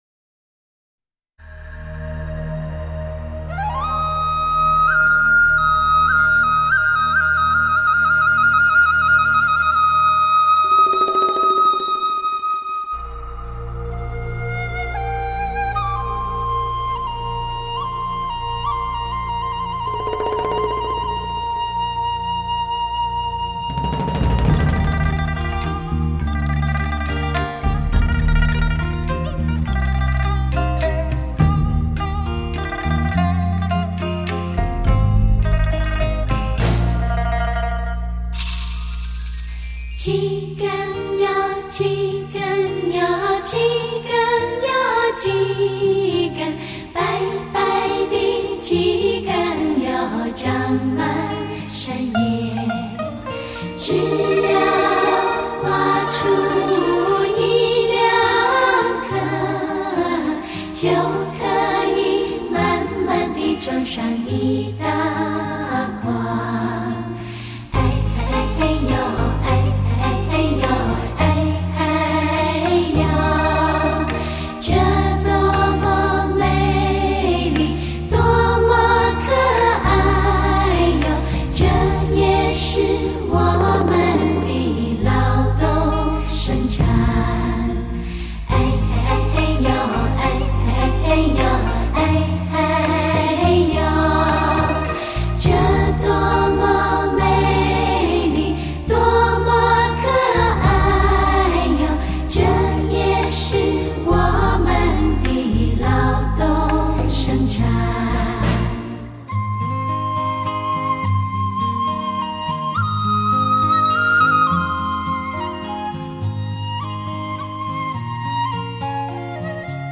DAM(数字模拟系统)